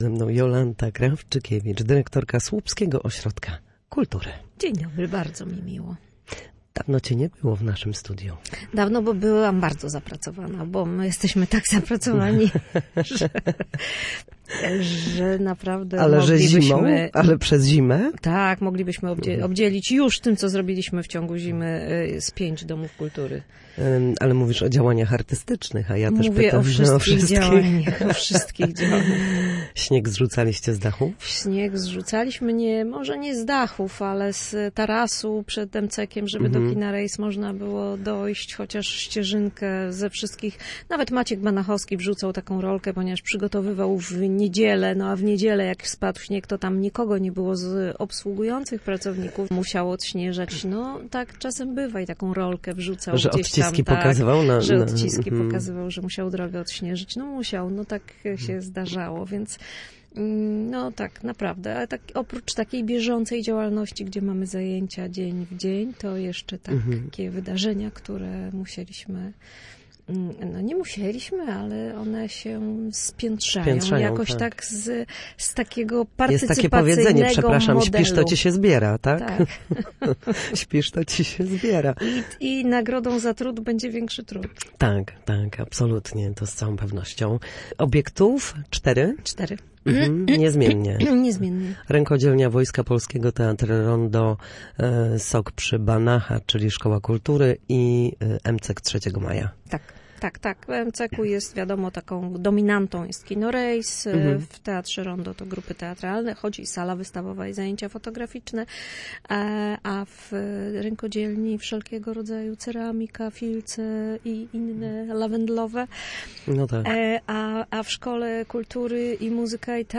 opowiadała w Studiu Słupsk